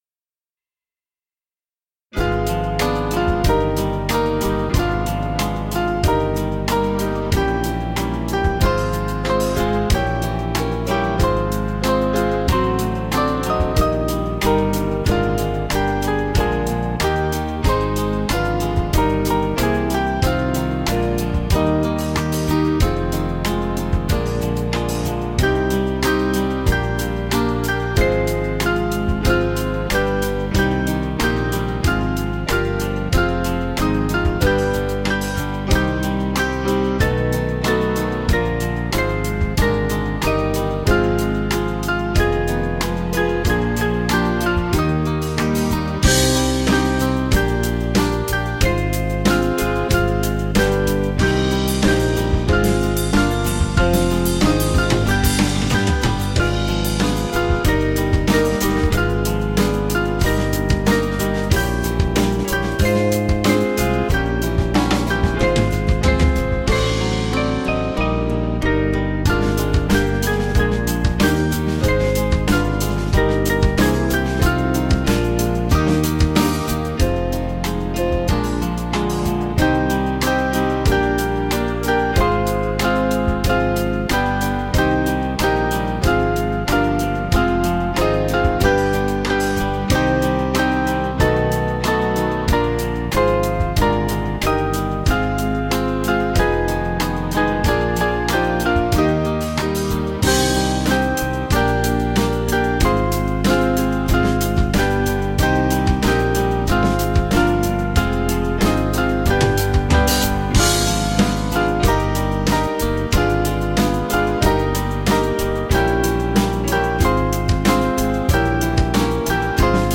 Small Band